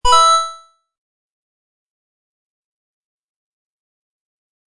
mario-coin-200bpm-82548.mp3